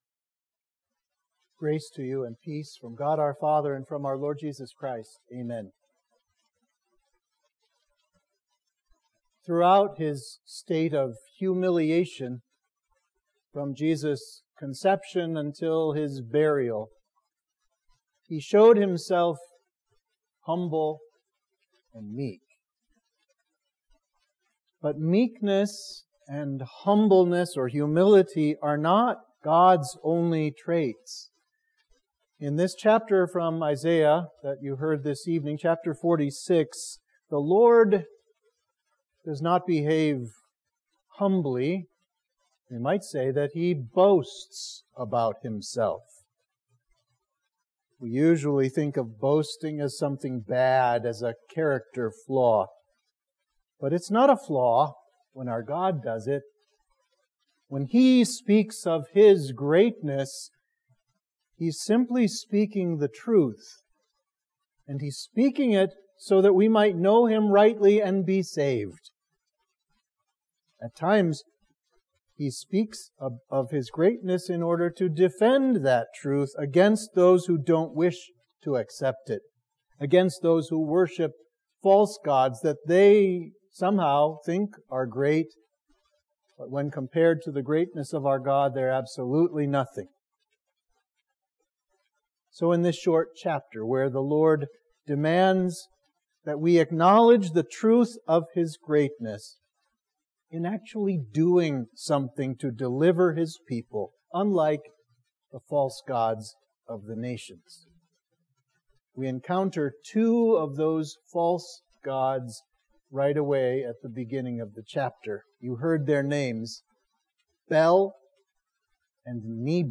Sermon for Midweek of Easter 4